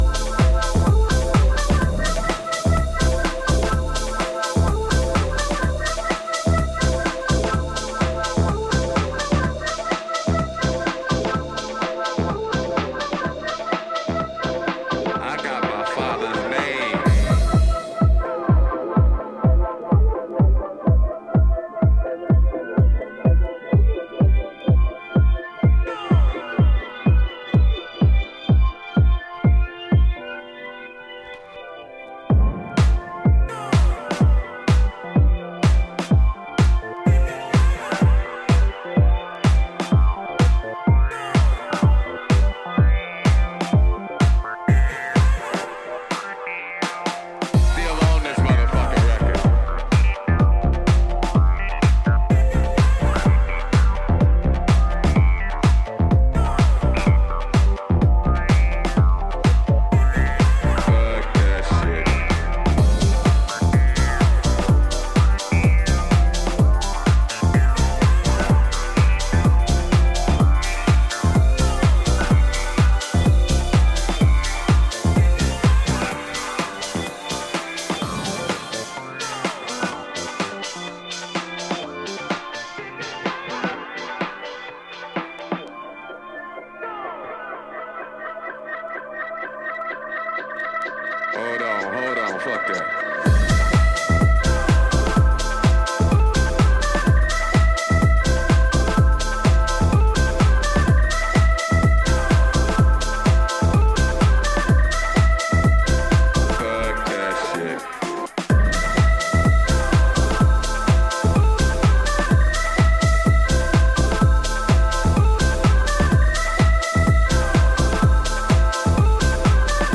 フロアやバーにてファンキーに効能するナイスコンピです！